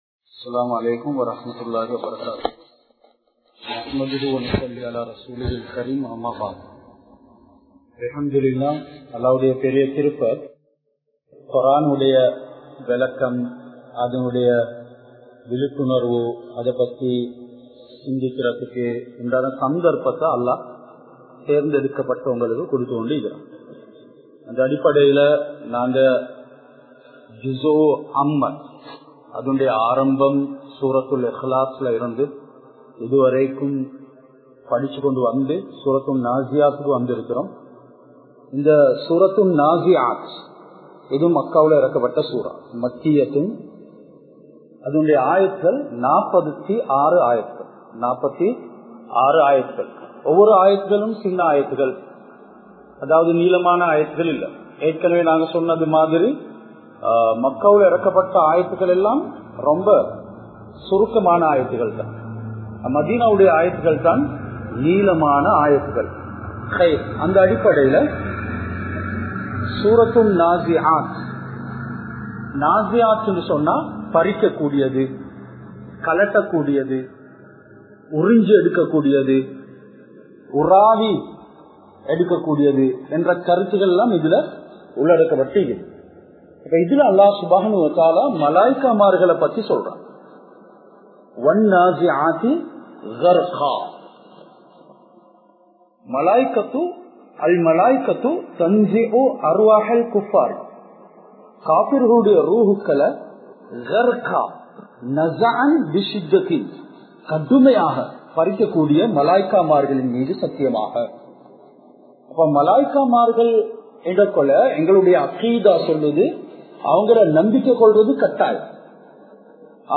Surah AnNaziath Versus 1 to 5 Thafseer Lesson-01 | Audio Bayans | All Ceylon Muslim Youth Community | Addalaichenai
Hameed Hall Furqaniyyah Arabic College